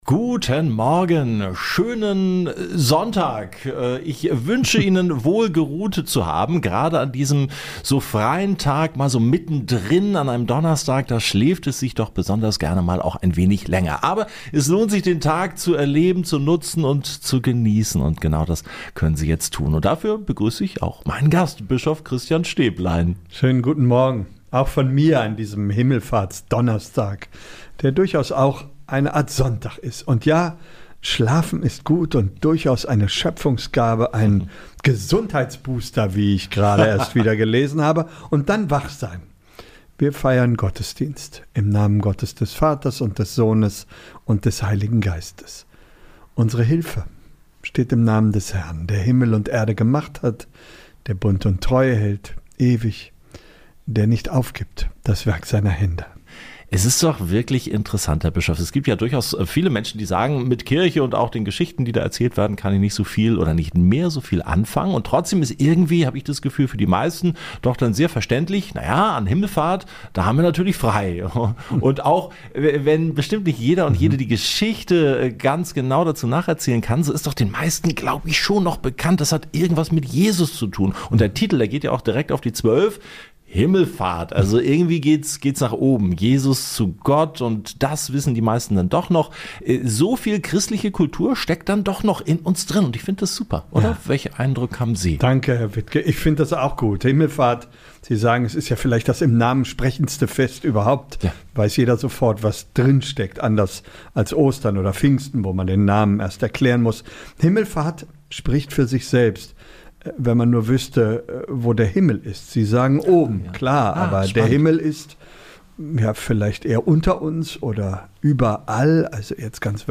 Ein anderes Sehen ~ Radio Paradiso - Gottesdienst im Gespräch Podcast